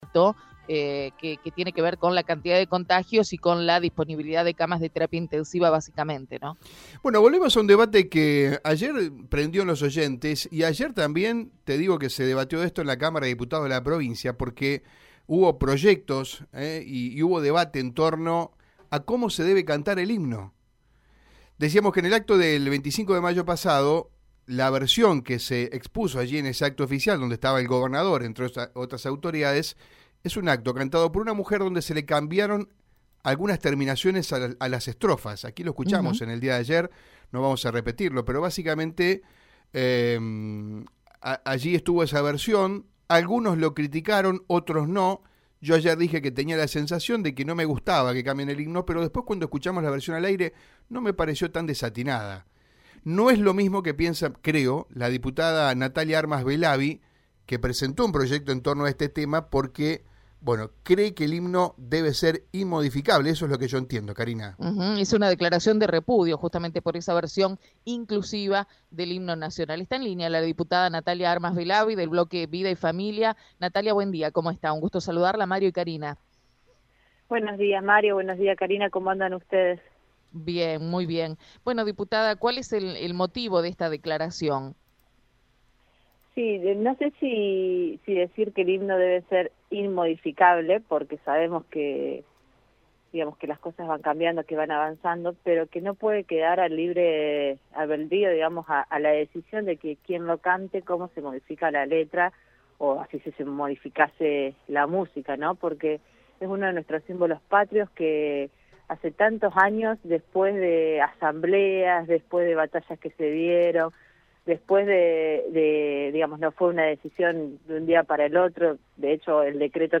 Así lo manifestó la diputada provincial, Natalia Armas Belavi, en Radio EME. La legisladora del bloque «Somos Vida y Familia», repudió los cambios en el himno nacional.